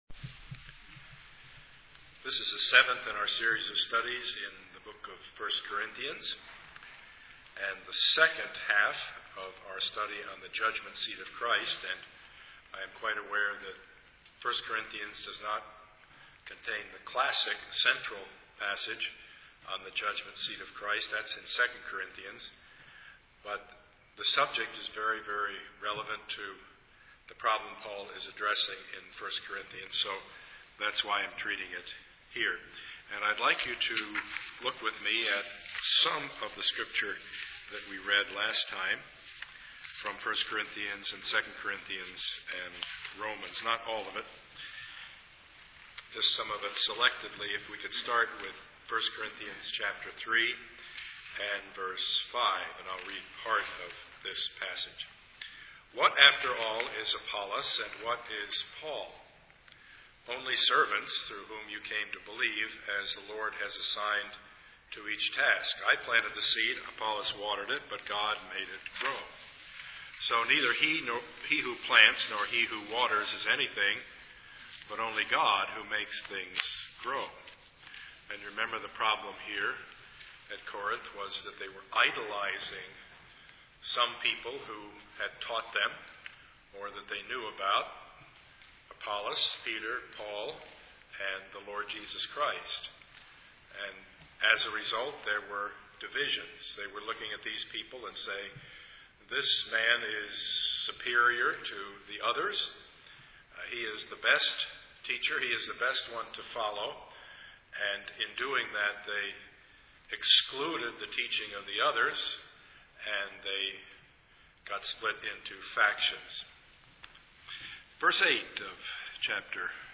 Service Type: Sunday morning
Part 7 of the Sermon Series